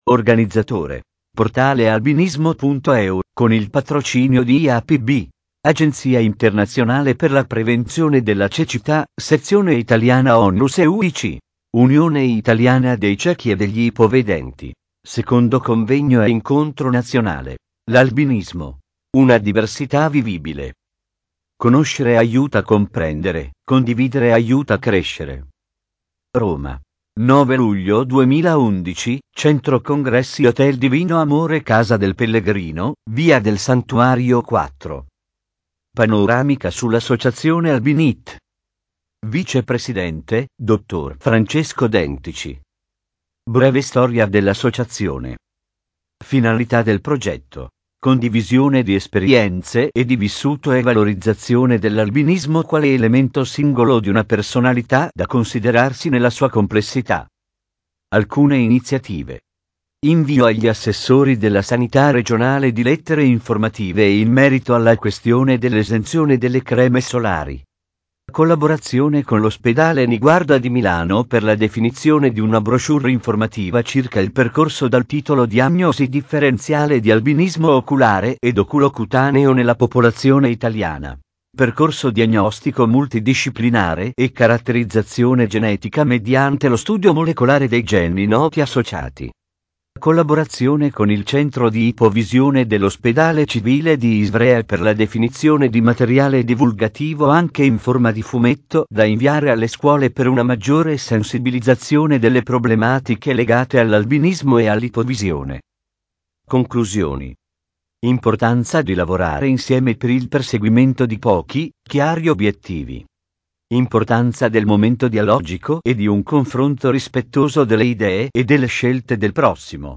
2011 - L’Albinismo: una diversità vivibile - 2° Convegno Nazionale